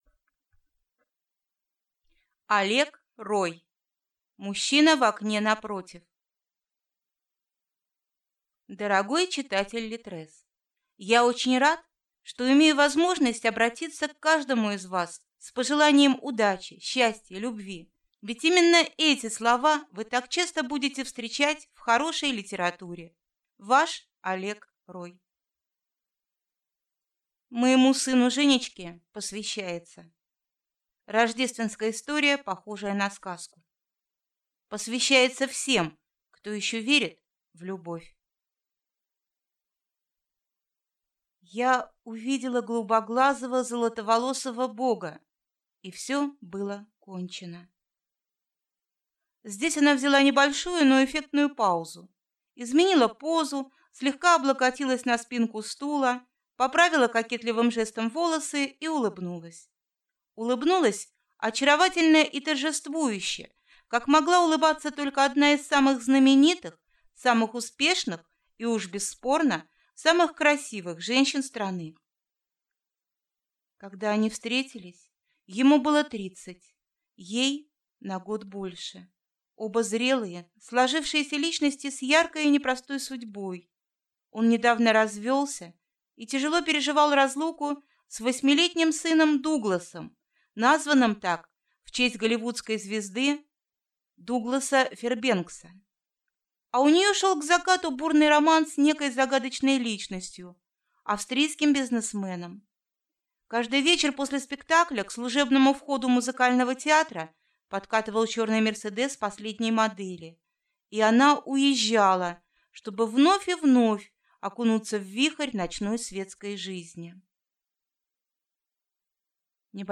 Аудиокнига Мужчина в окне напротив | Библиотека аудиокниг